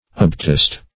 Obtest \Ob*test"\, v. i.